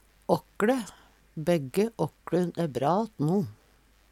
åkkLe - Numedalsmål (en-US)